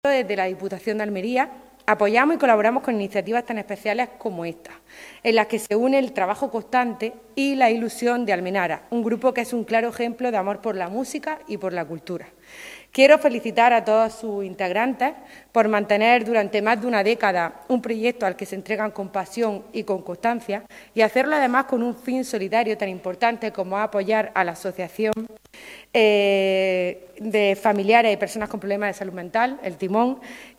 ALMUDENA-MORALES-diputada-cultura.mp3